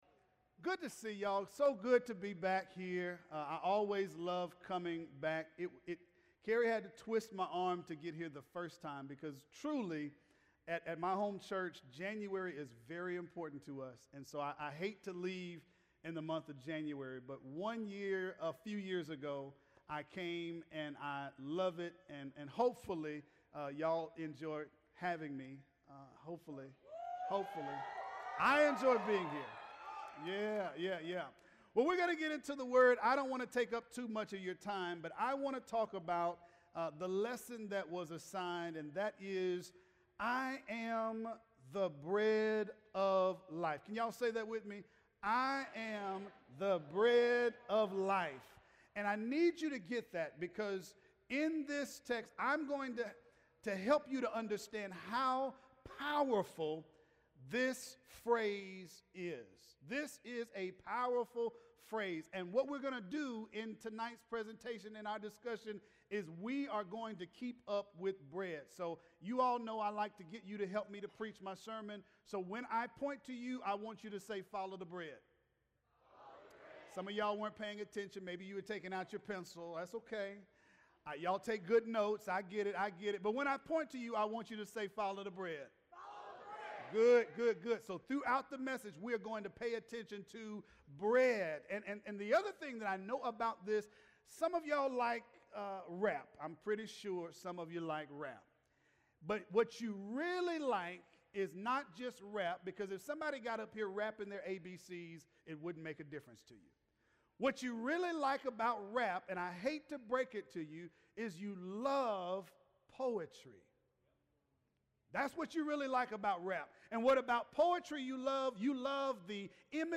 Series: "I AM" Refresh Retreat 2026